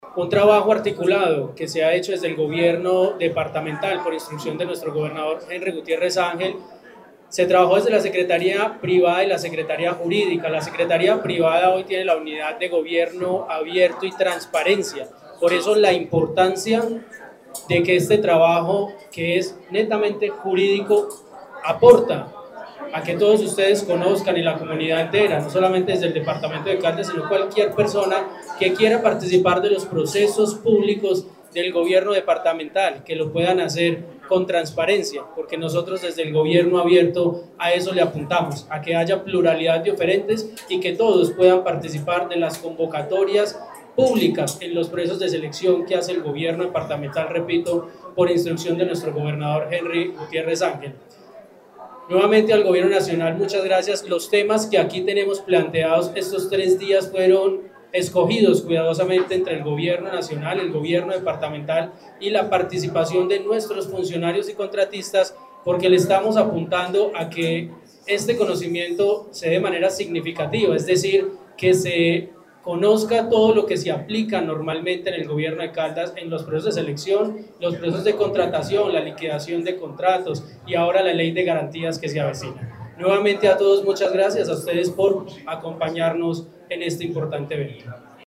Con la presencia del director general de la Agencia Nacional de Contratación Pública-Colombia Compra Eficiente, Cristóbal Padilla Tejeda, y el gobernador de Caldas, Henry Gutiérrez Ángel, se instaló en Manizales el Foro de Contratación Estatal y Compras Públicas.
Secretario Privado, Ronald Fabián Bonilla Ricardo.